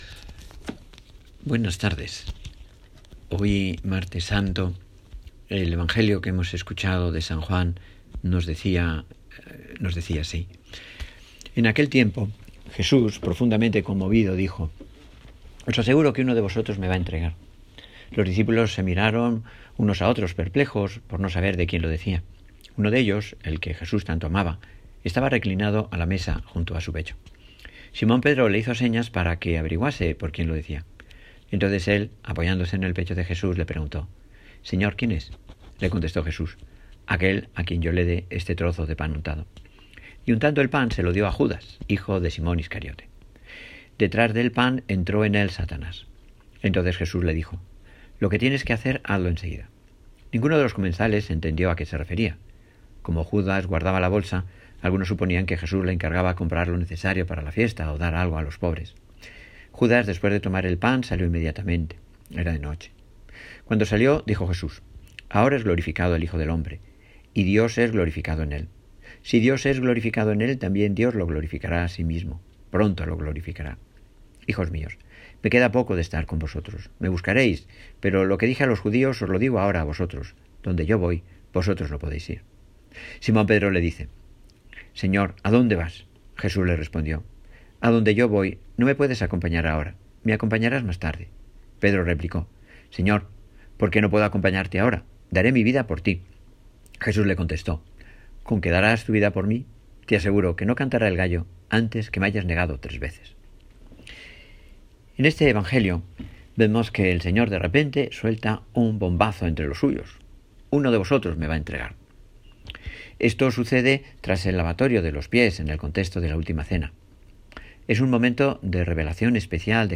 Nuestro Párroco nos propone su meditación y, también, un himno para este Martes Santo.